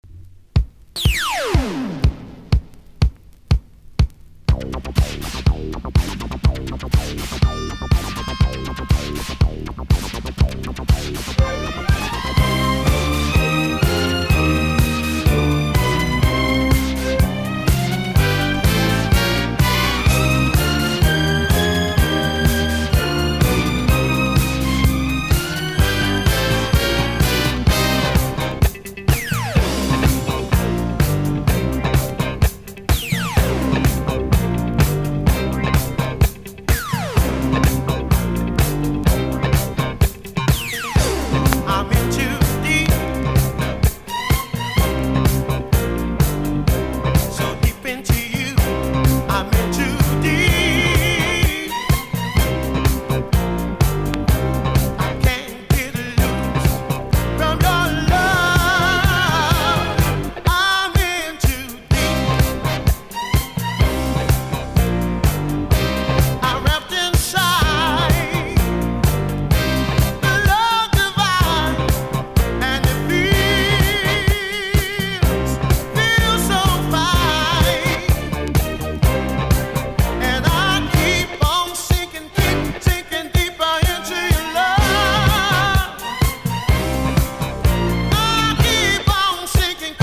FREE SOUL